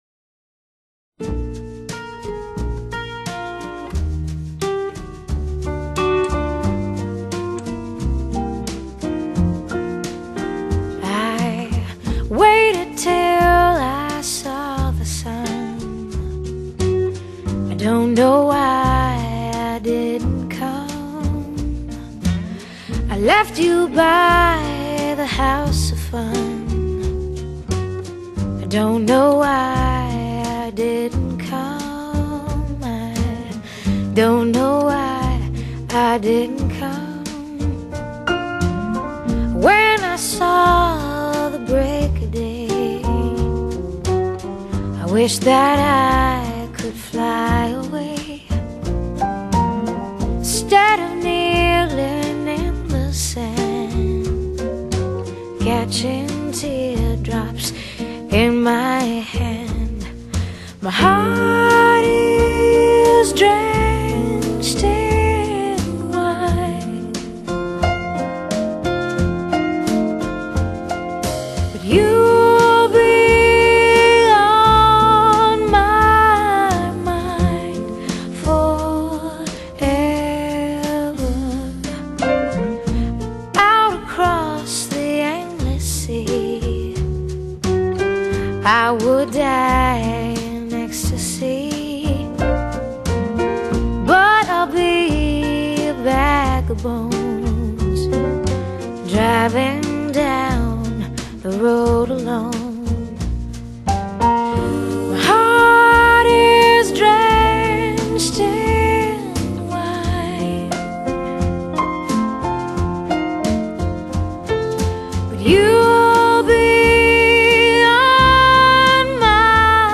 Genre: Blues, Vocal Jazz